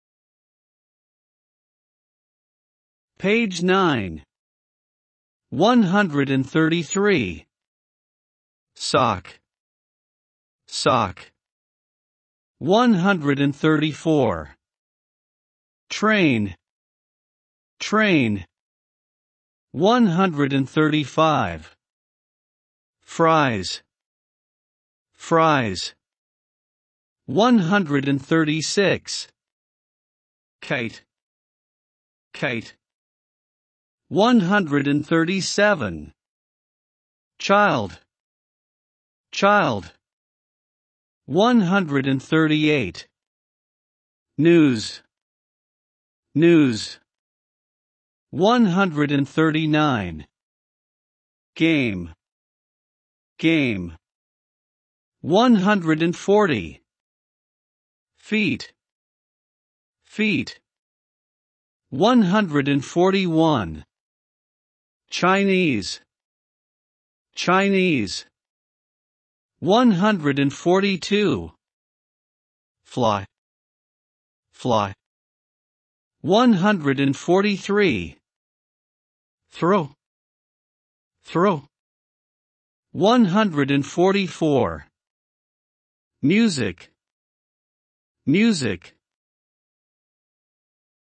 三下英語單字語音檔P9 （最近一週新上傳檔案）